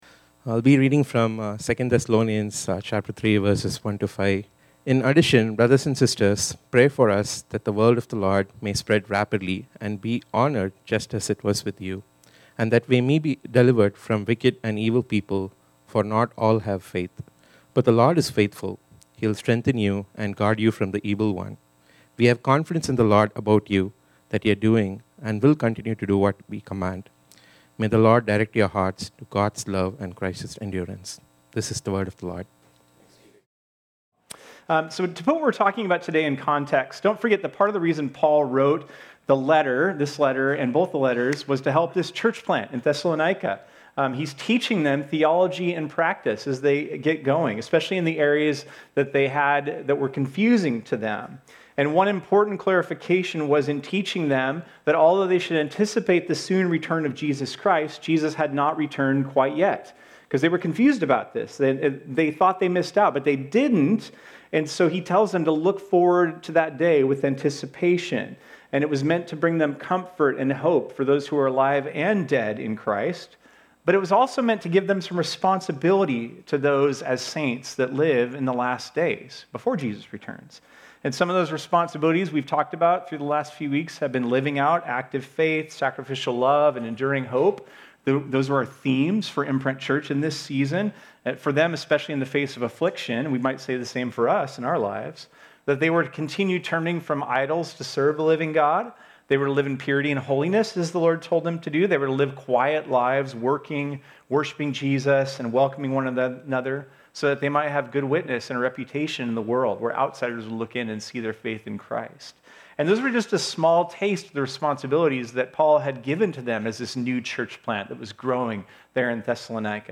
This sermon was originally preached on Sunday, September 21, 2025.